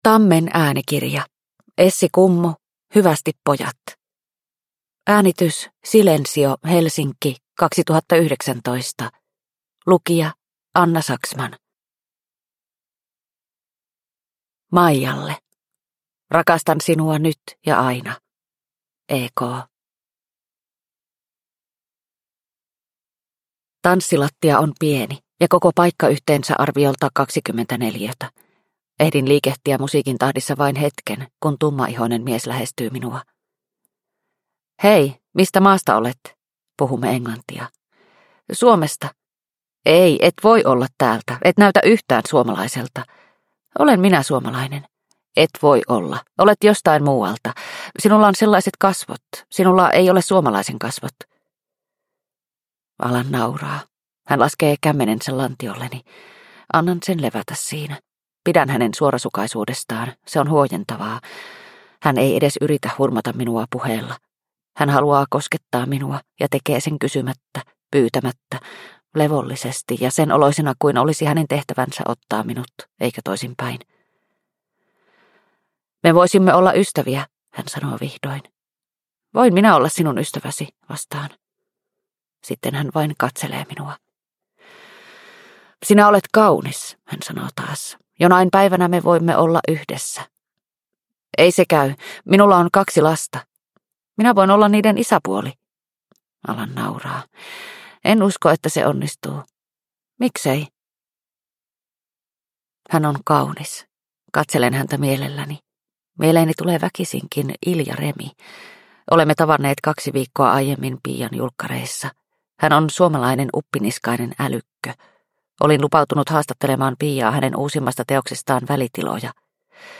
Hyvästi pojat – Ljudbok – Laddas ner